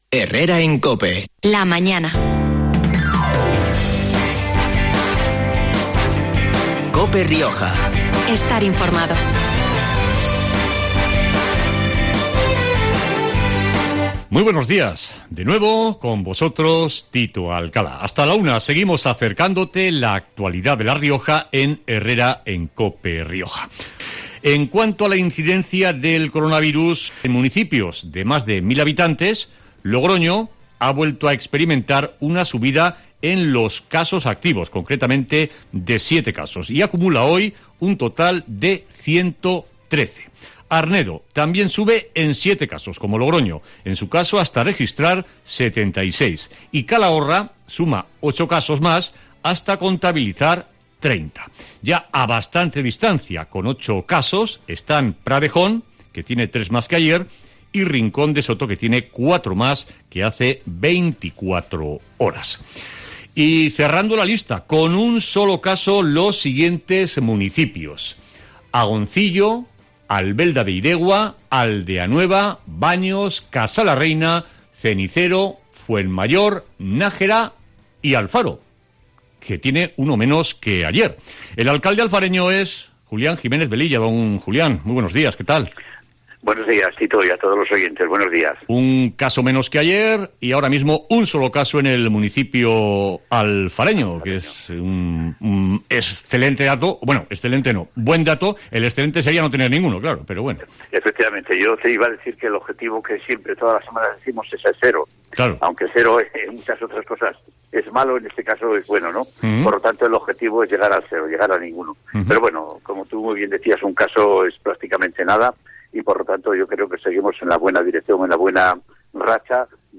Entrevista en COPE Rioja a Julián Jiménez Velilla, alcalde de Alfaro